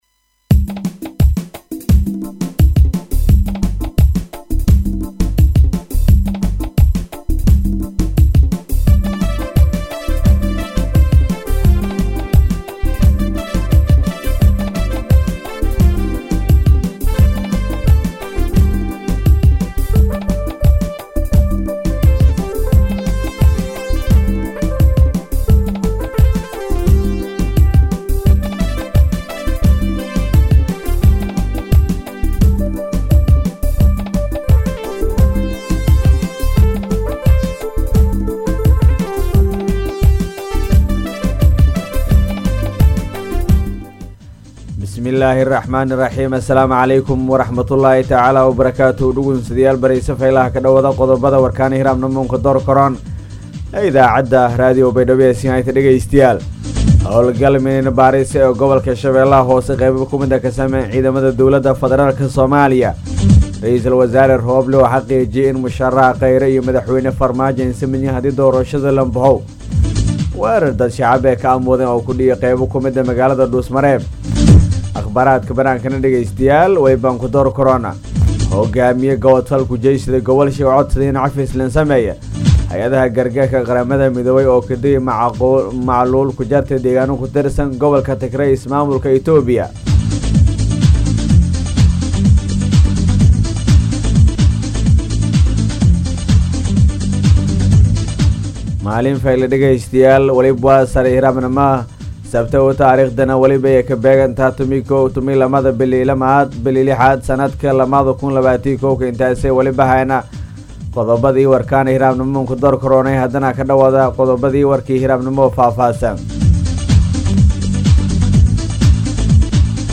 warka-subax.mp3